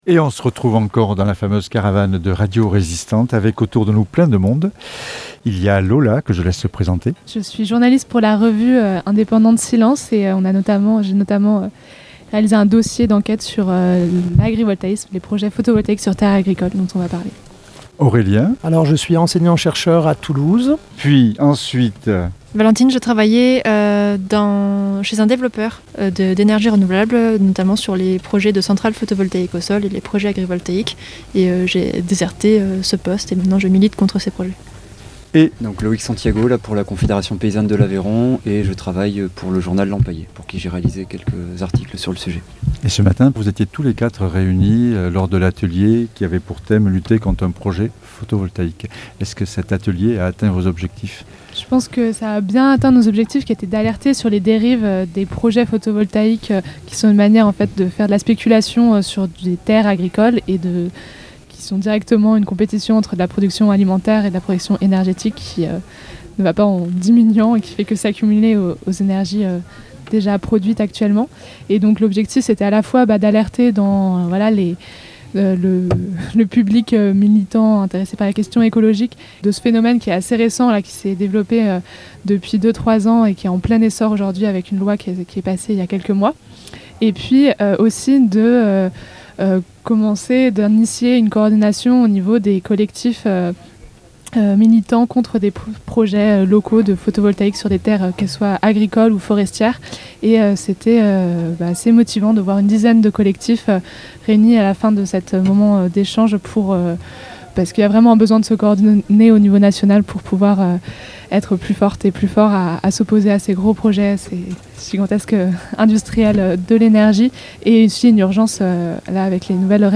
Enregistrée le vendredi 4 août 2023, sous le chapiteau Salamandre. Est-ce que 500 000 hectares vont être recouverts de photovoltaïque ?